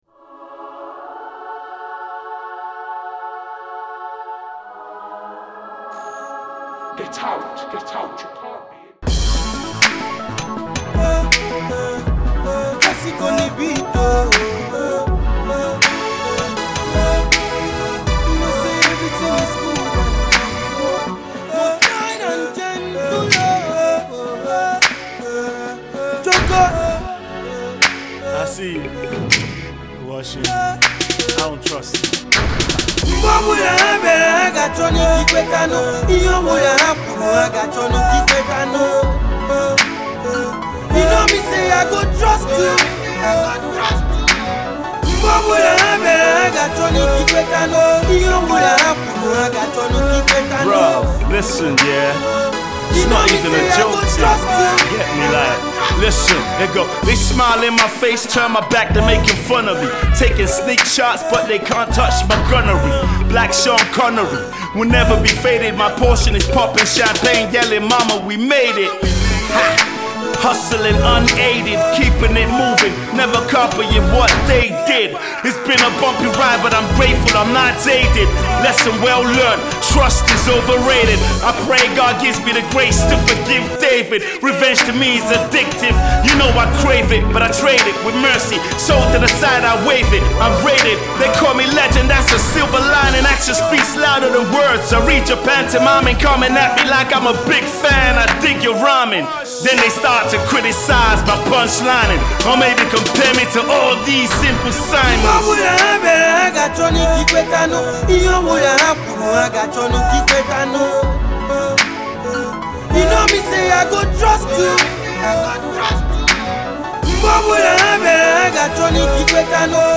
Hip-Hop
Revered Nigerian Rapper